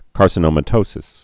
(kärsə-nōmə-tōsĭs)